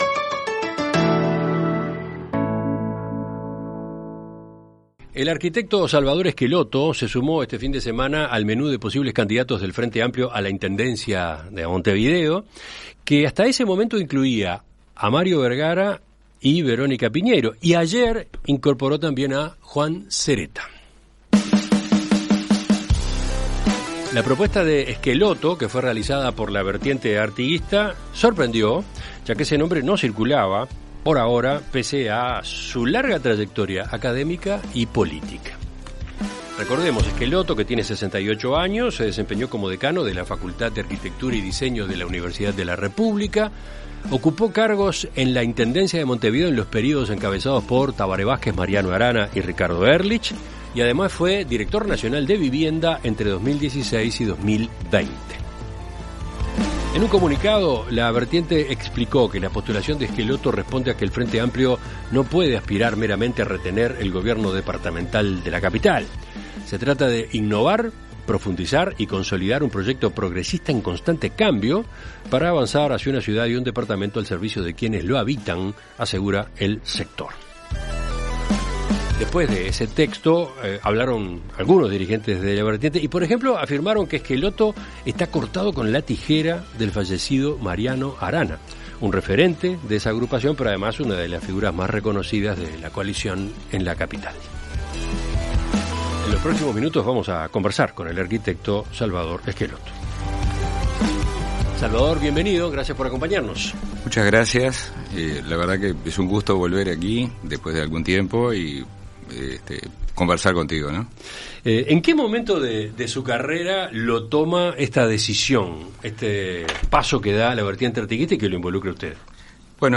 En Perspectiva Zona 1 – Entrevista Central